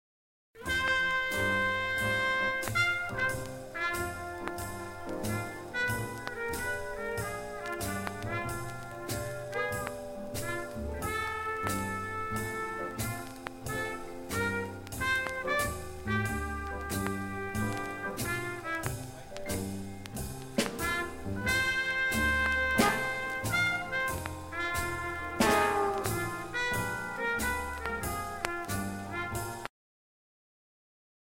The guest musician was Clark Terry on the trumpet.
Jazz vocals